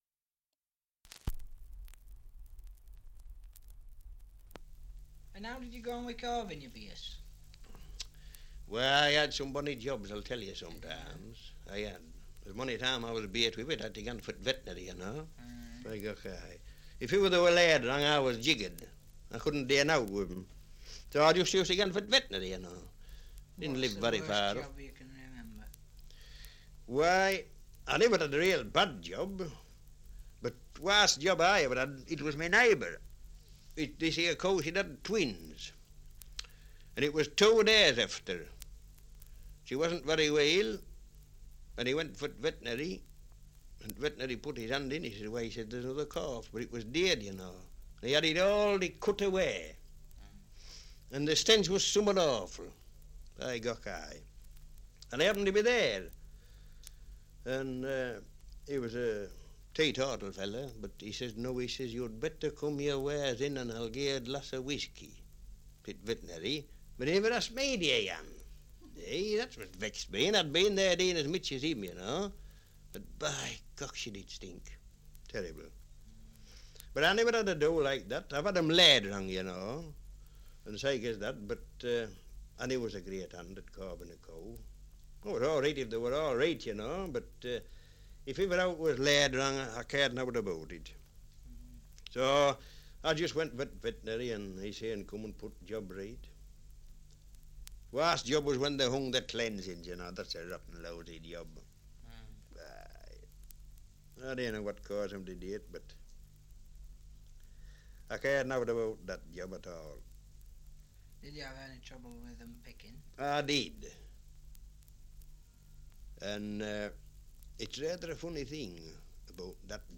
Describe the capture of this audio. Survey of English Dialects recording in Stokesley, Yorkshire 78 r.p.m., cellulose nitrate on aluminium